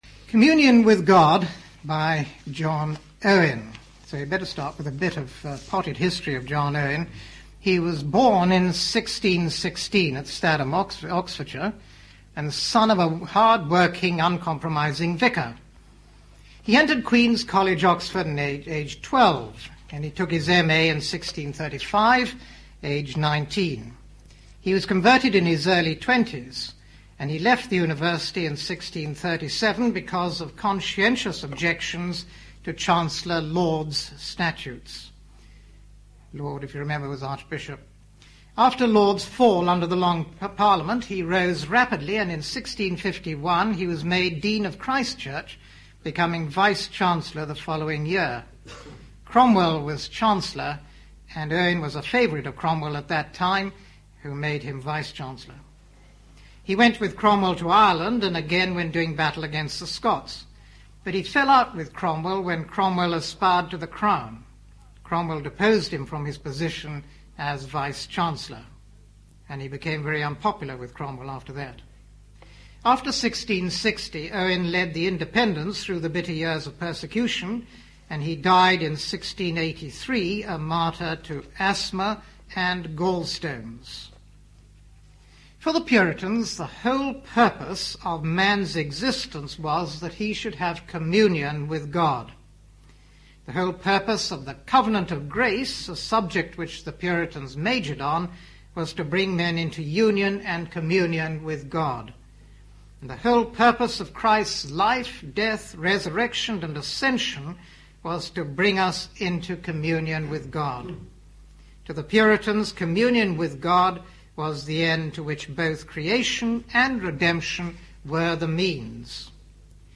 1993 Autumn Lectures